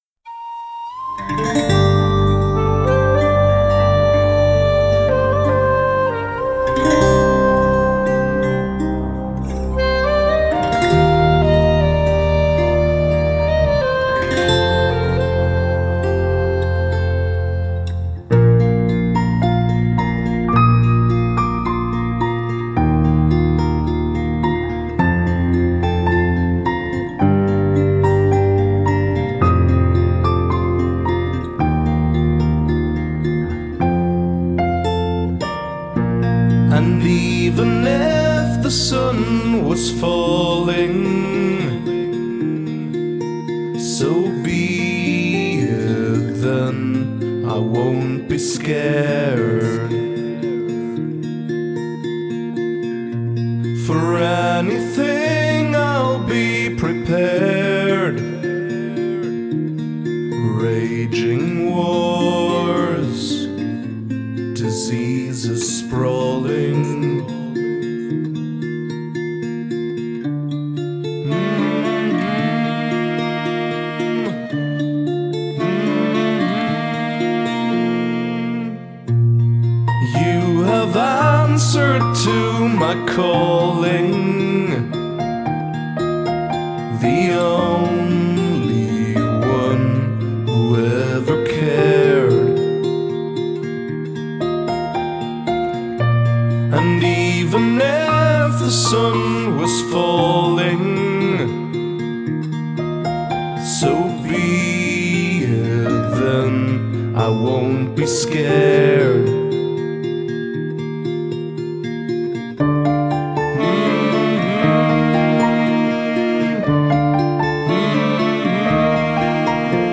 Rondel